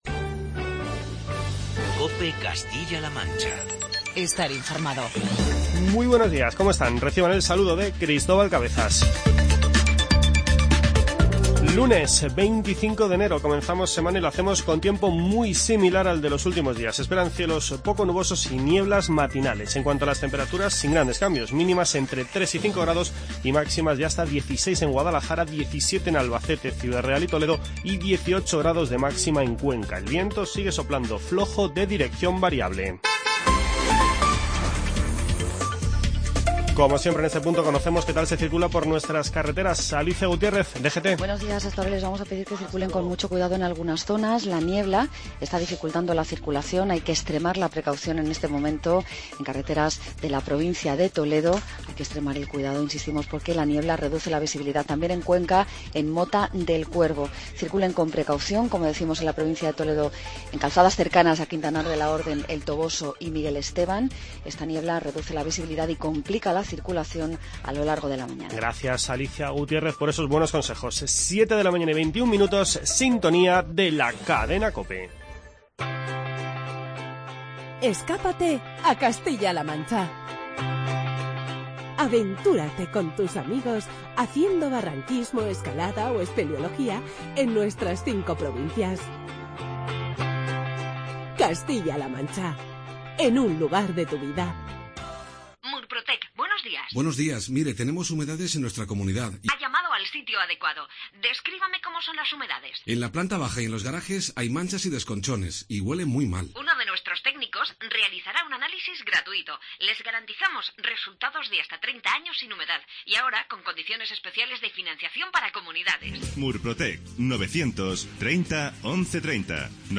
Escuchamos las palabras de María Dolores Cospedal en el acto celebrado en las últimas horas en Guadalajara.